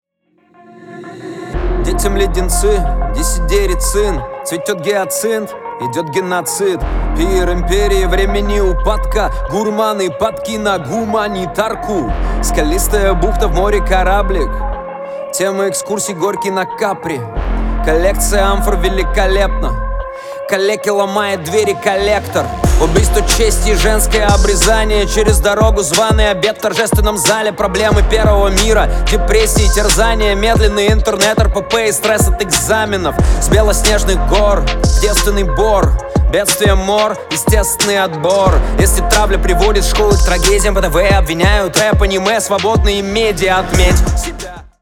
• Качество: 320, Stereo
мужской голос
русский рэп
басы
качающие
биты